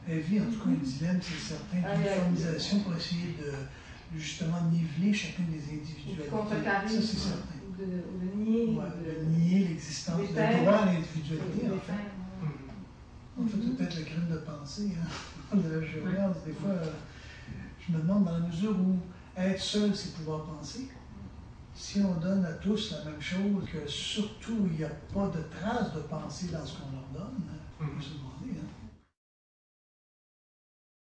enregistré en public lors d’une causerie de la Phonothèque le 31/05/2001